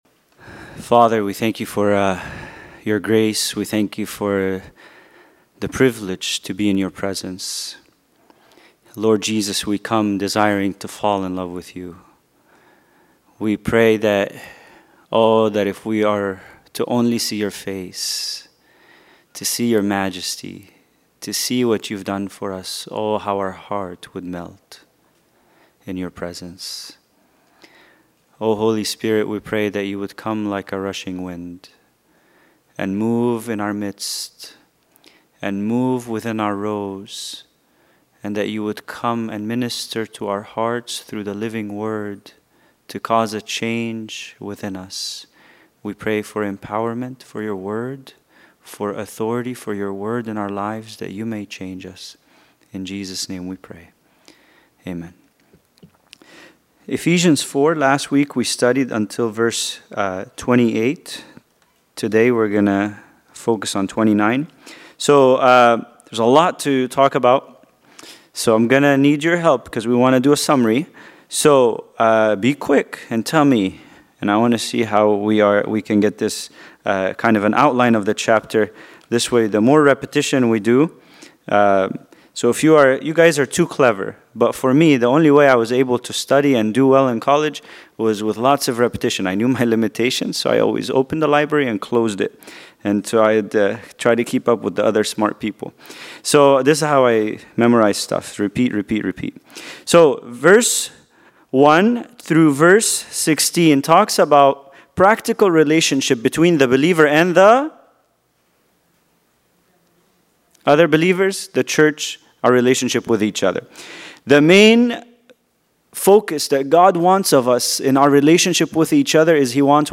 Bible Study: Ephesians 4:29